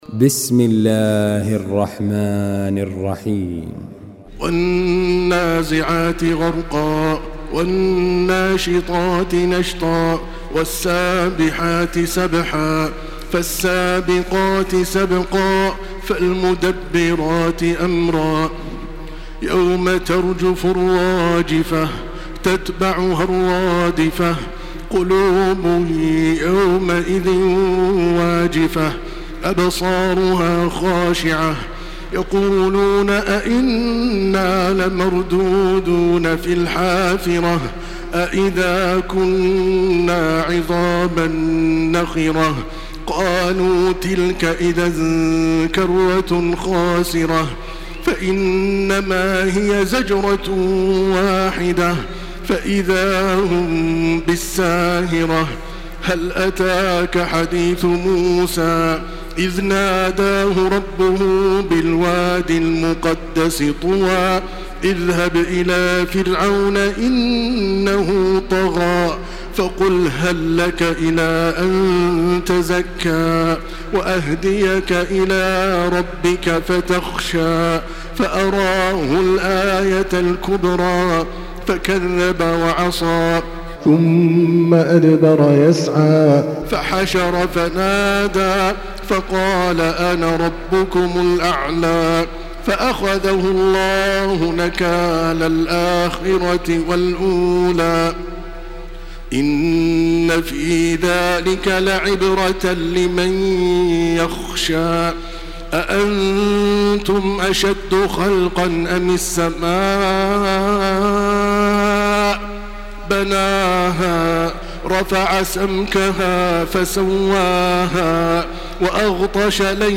Surah আন-নাযি‘আত MP3 in the Voice of Makkah Taraweeh 1429 in Hafs Narration
Murattal